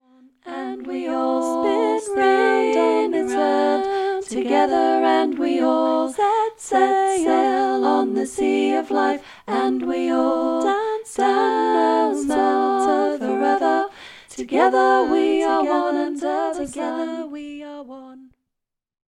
A song for group singing
Parts – 3